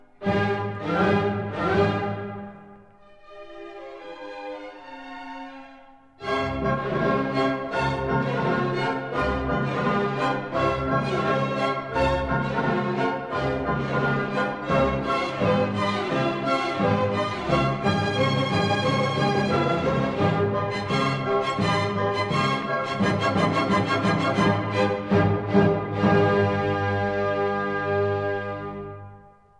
This is the Allegro vivace in a performance by the